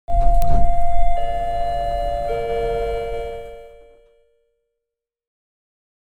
liftBong
chime ding doors elevator floor level lift sound effect free sound royalty free Gaming